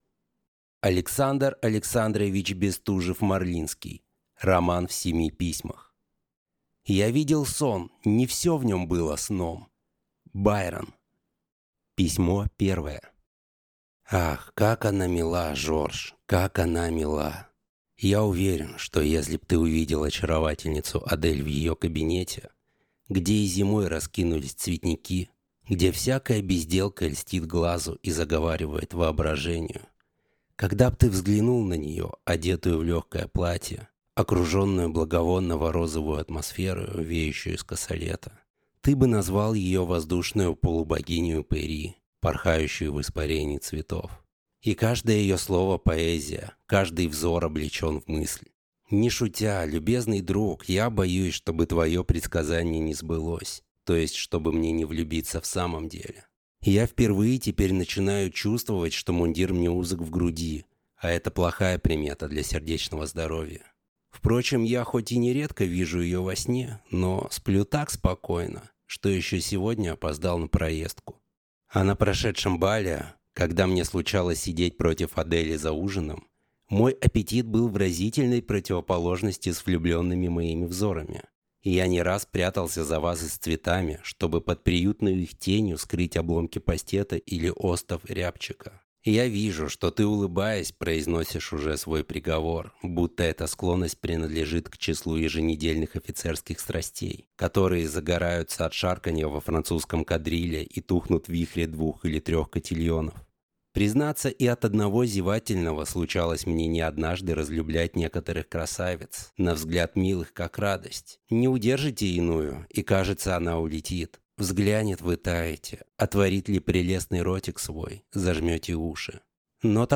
Аудиокнига Роман в семи письмах | Библиотека аудиокниг